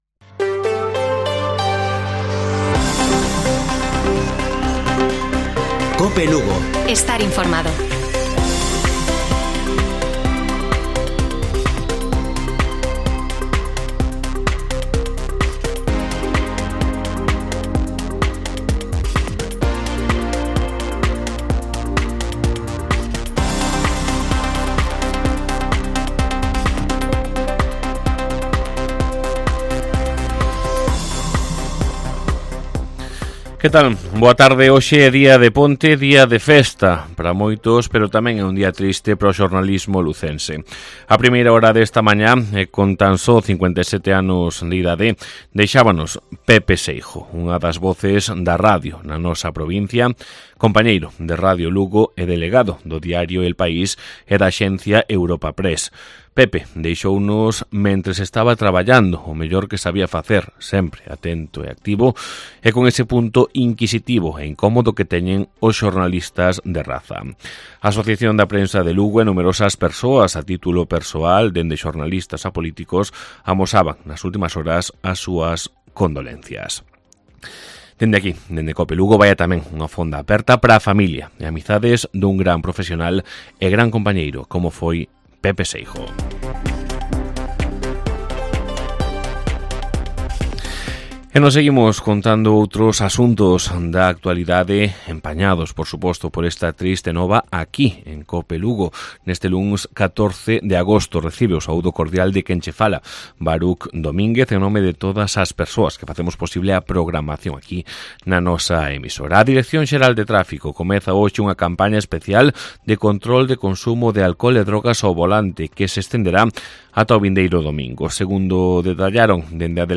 Informativo Mediodía de Cope Lugo. 14 de agosto. 14:20 horas